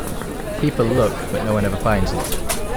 crowdNoise.wav